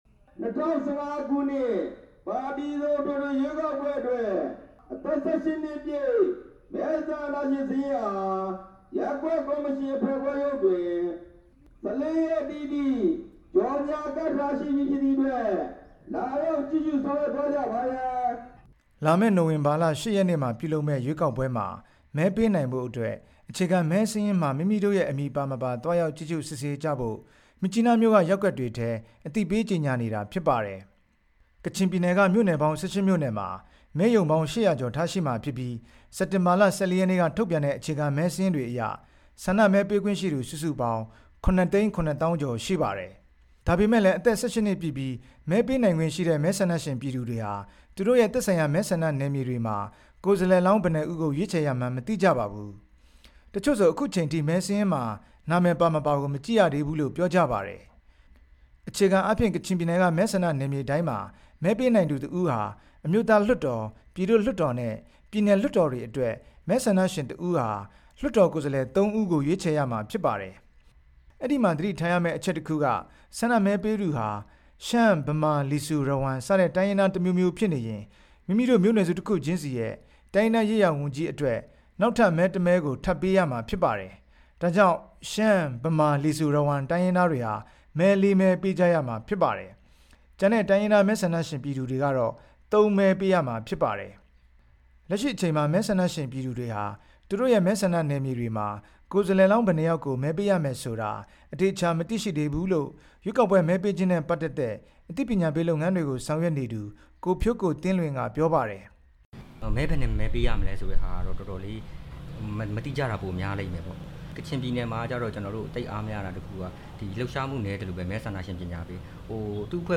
မြစ်ကြီးနား၊ ဝိုင်းမော်မြို့နယ်တွေက ရပ်ကွက်၊ ကျေးရွာတွေနဲ့ ဈေးတွေထဲမှာ